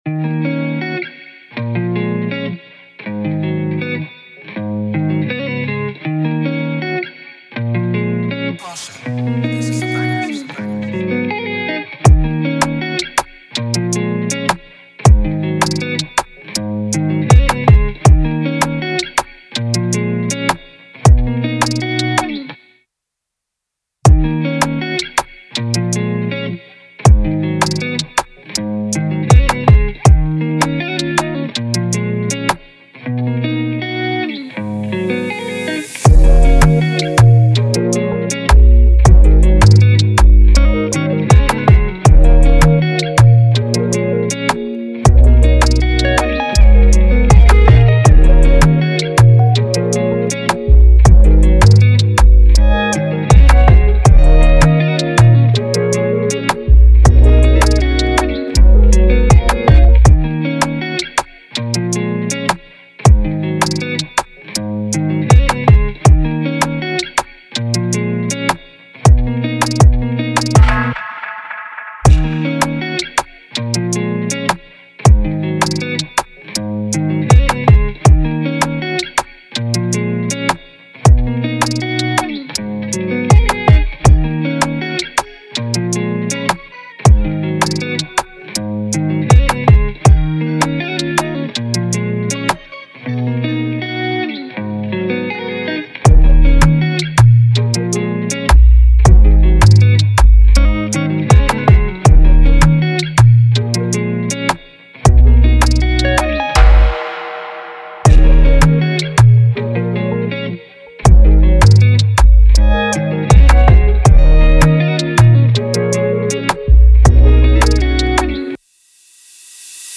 2024 in Dancehall/Afrobeats Instrumentals